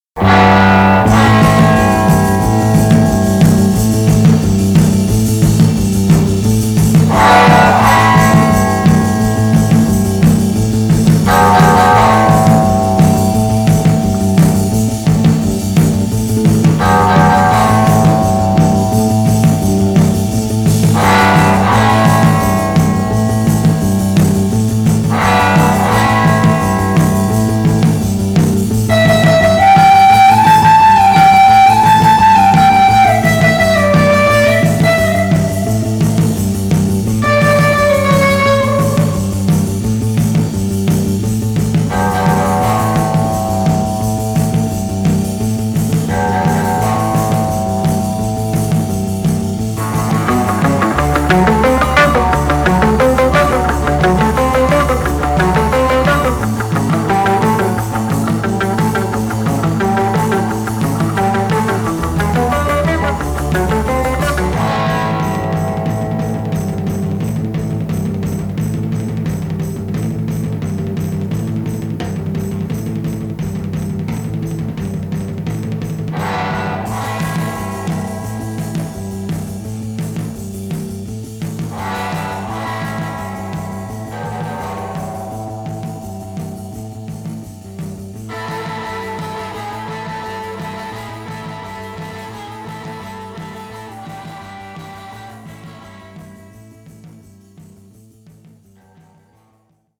the mesmerising